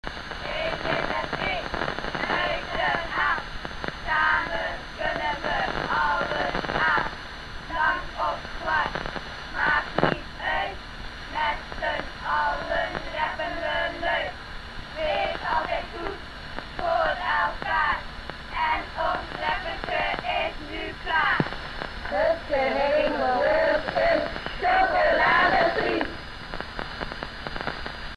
ARISSAT-1 VOICE MESSAGE 05. September 2011 at 15:29:18 UTC